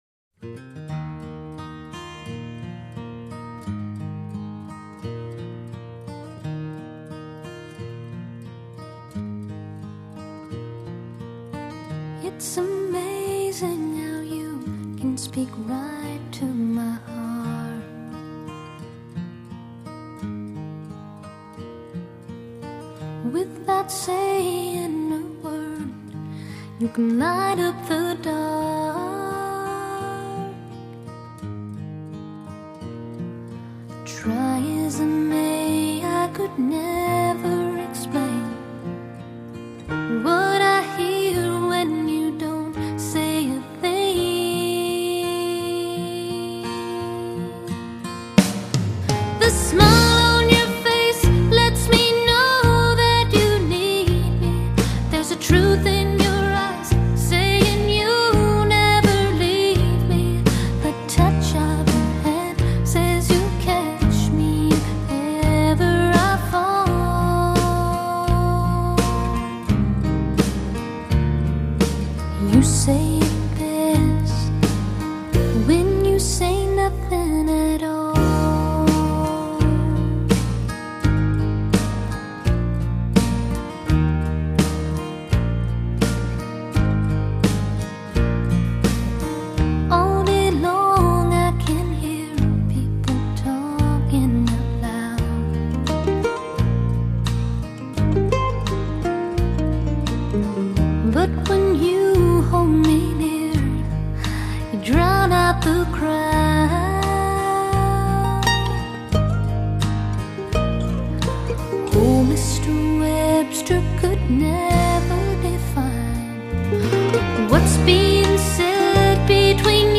歌谱曲音不毒死不罢休，限量发烧试音天碟毒霸。
这张CD选取在hifi音响迷中16种毒声＝HI-END级的录音